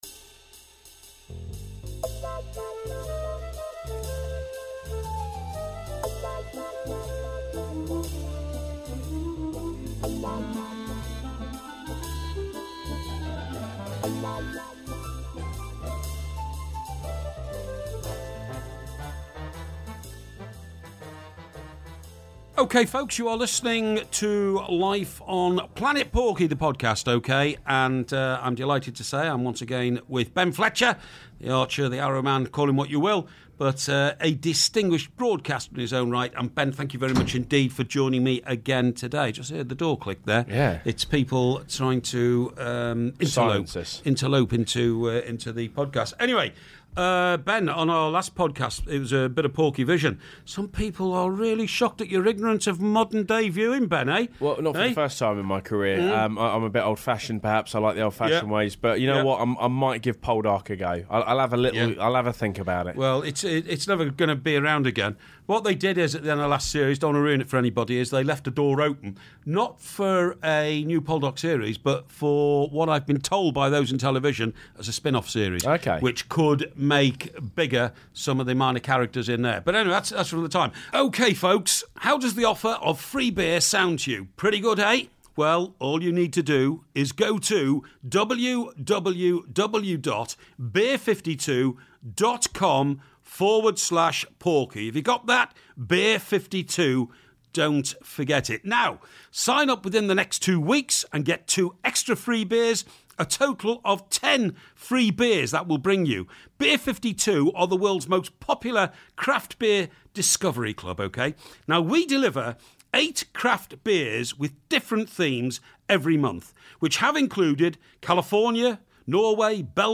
It's more considered pub debate for your ears...